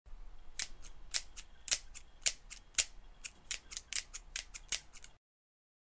Scissors
Category: Sound FX   Right: Personal
Tags: haircuts